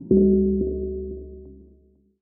UIMvmt_Game Over Ghostly Haunted 03.wav